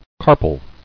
[car·pal]